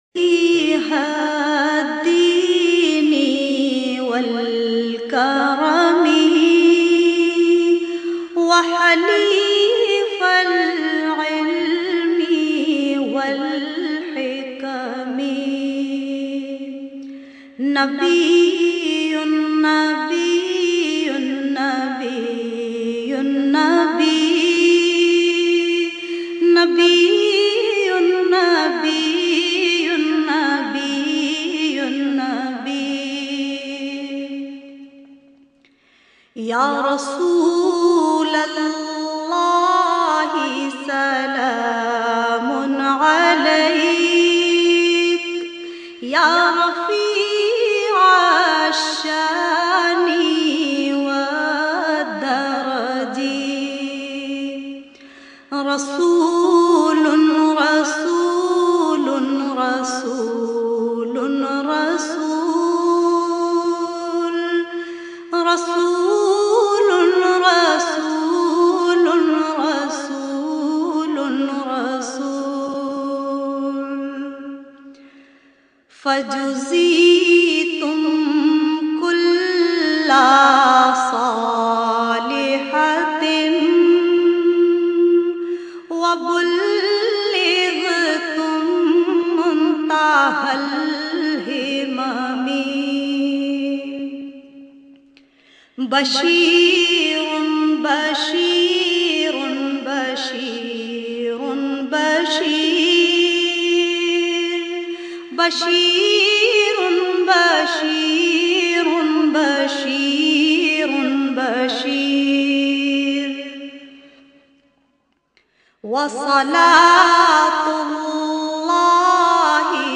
She has her very own style of reciting Naats.